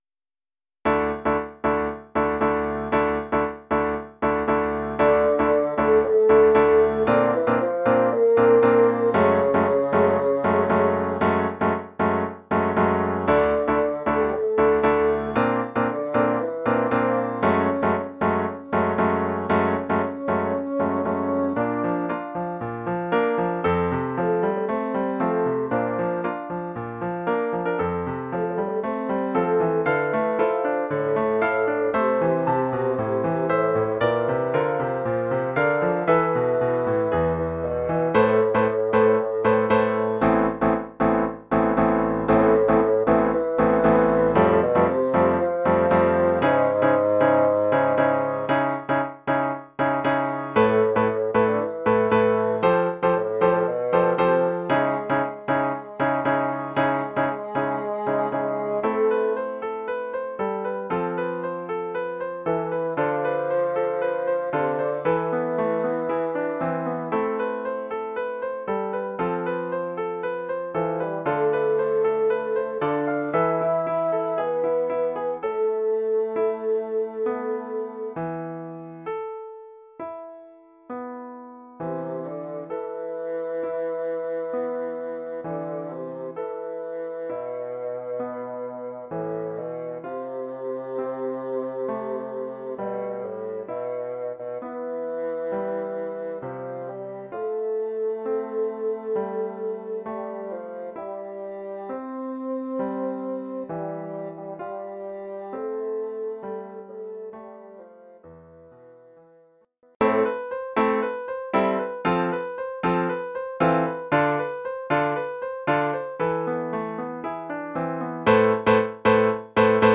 Formule instrumentale : Basson et piano
Oeuvre pour basson et piano.